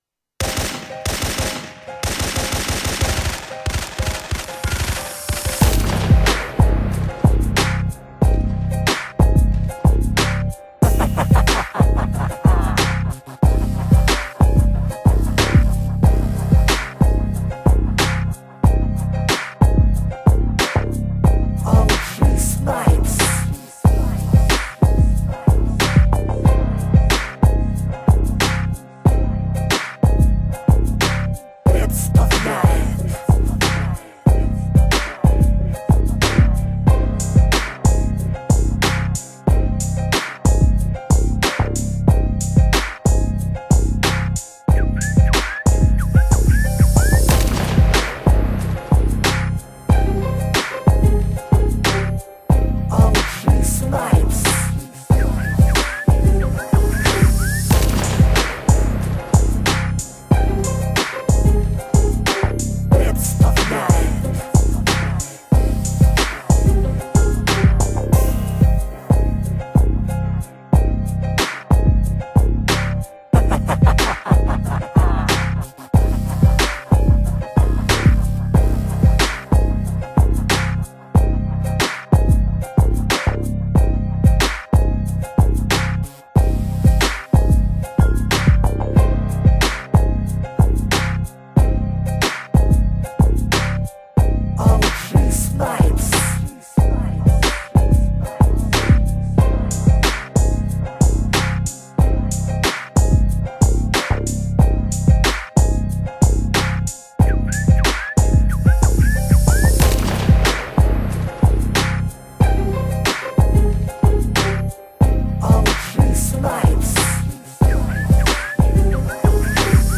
West Coast Beat